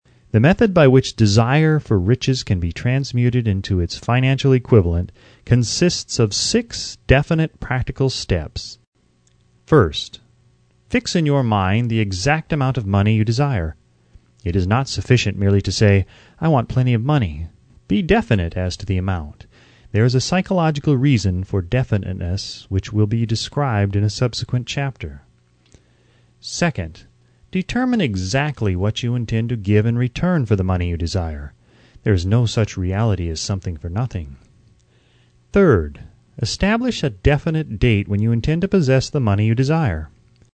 think-and-grow-rich-by-napolean-hill-mp3-audio-book-free.mp3